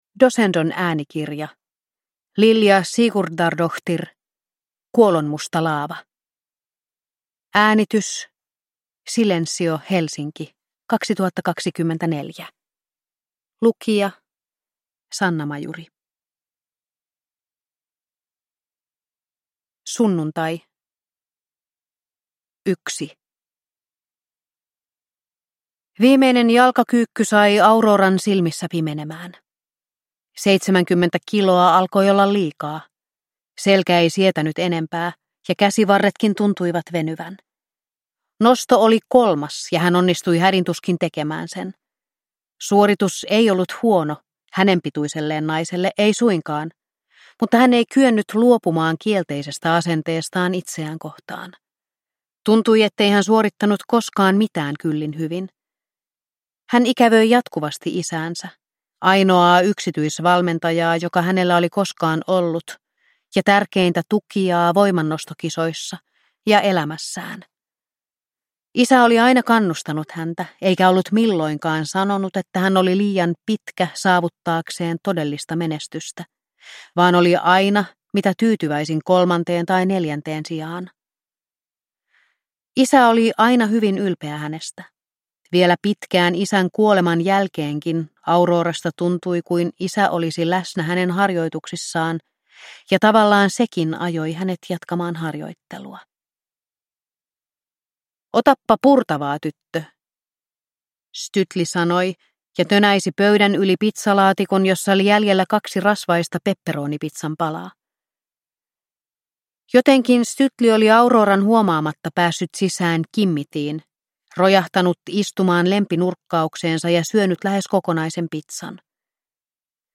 Kuolonmusta laava – Ljudbok